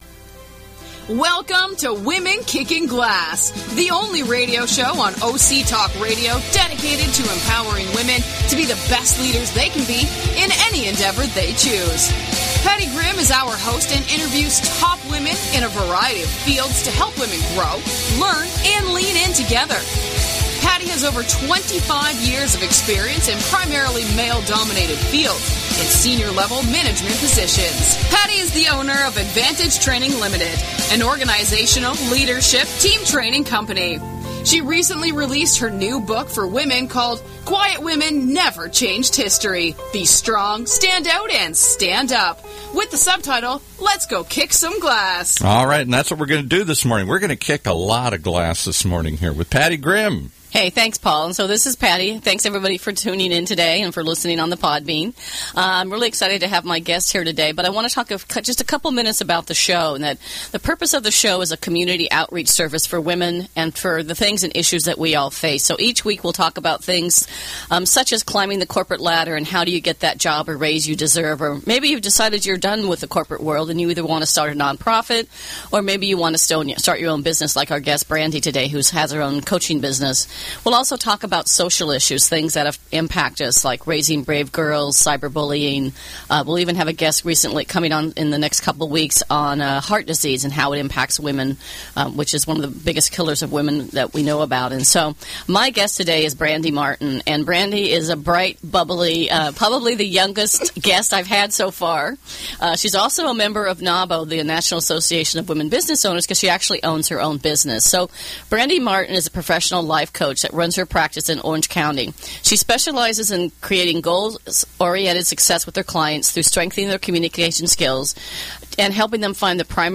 Radio Show
Great intervew and insightfuls from a young woman business owner.